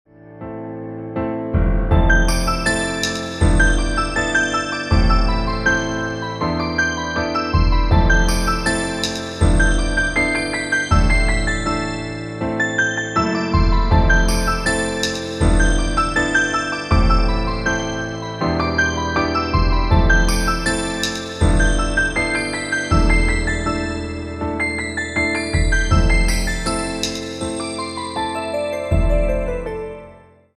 • Качество: 192, Stereo
инструментальные
колокольчики